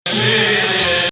Hijaz 3